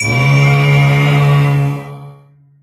Dome SFX
技能音效
CR_monk_dome_sfx_01.mp3